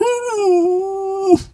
bear_pain_whimper_14.wav